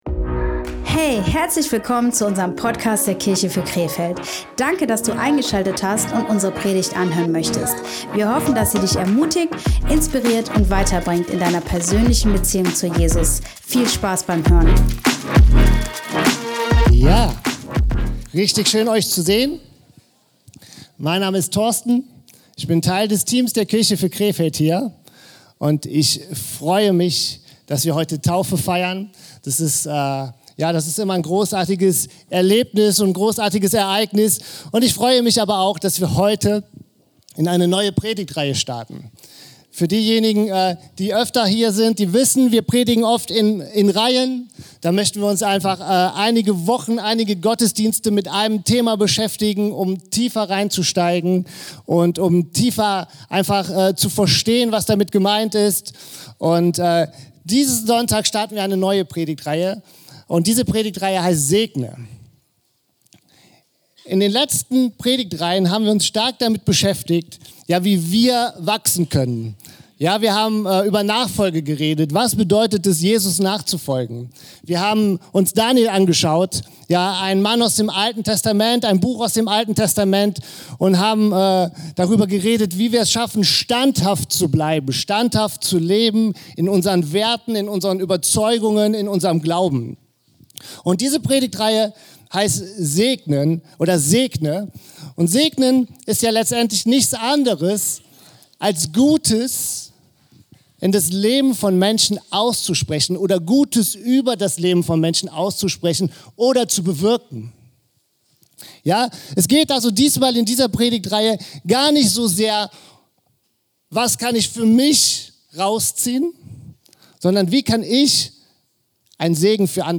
Wir starten in unsere neue Predigtreihe S.E.G.N.E. Wie können wir ein Segen im Leben anderer Menschen sein? Die erste Möglichkeit und der Titel unserer ersten Predigt lautet „Starte mit Gebet“.